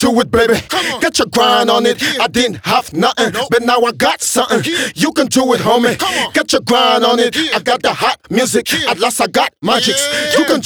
Сэмпл хип-хоп вокала: Rap Do It Double Hook B
Тут вы можете прослушать онлайн и скачать бесплатно аудио запись из категории «Hip-Hop».
Sound_09640_Rap_Do_ItDoubleHookB.ogg